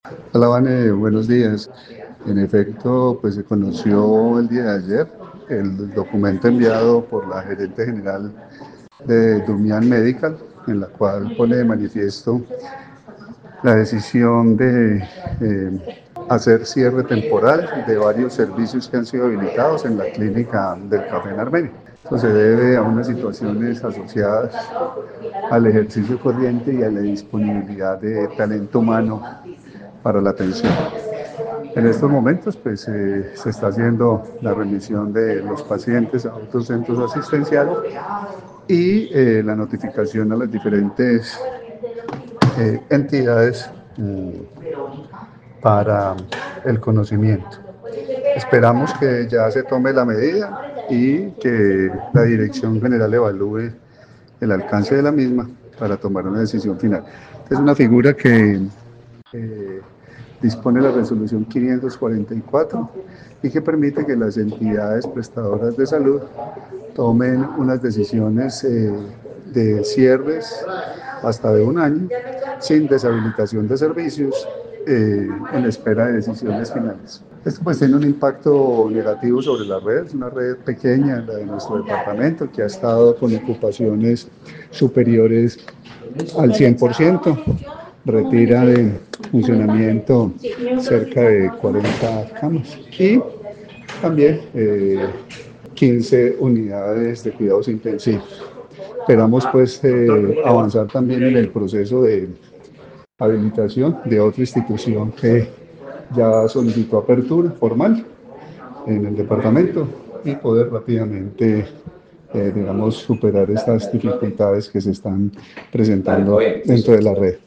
Secretario de Salud del Quindío, Carlos Alberto Gómez